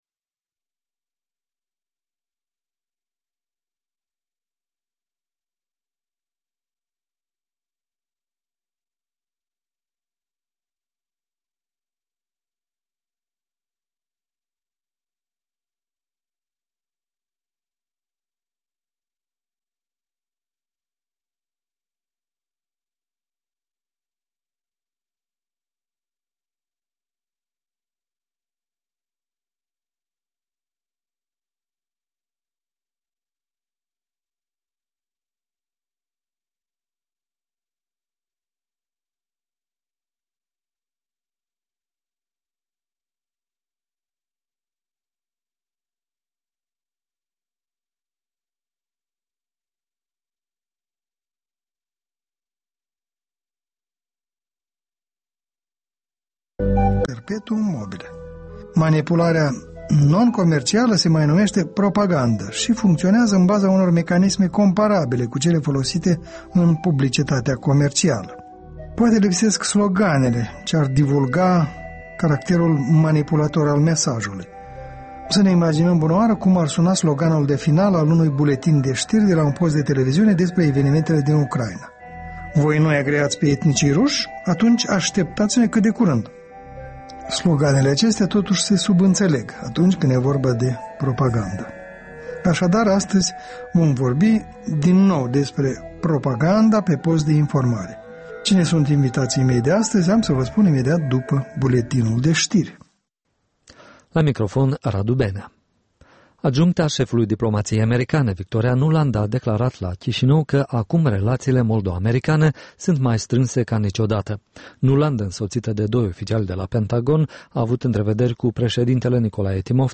O discuţie la masa rotundă